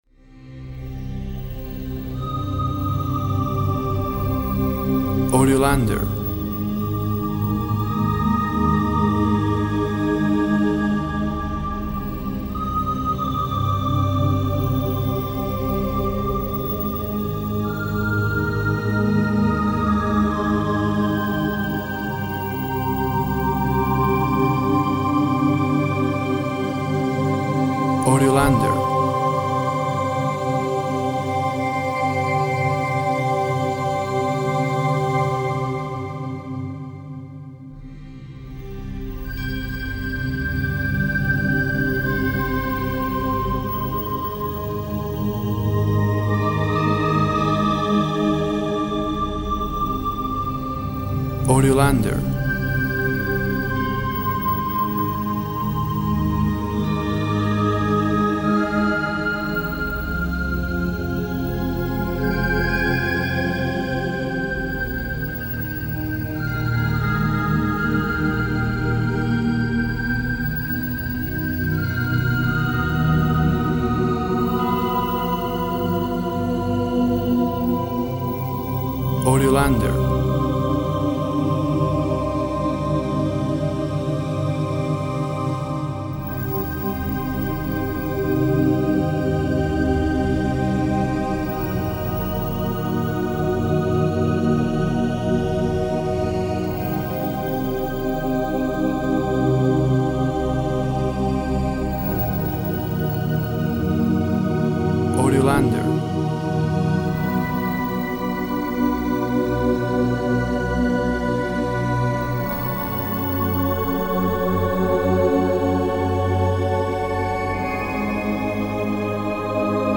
WAV Sample Rate 24-Bit Stereo, 44.1 kHz
Tempo (BPM) 60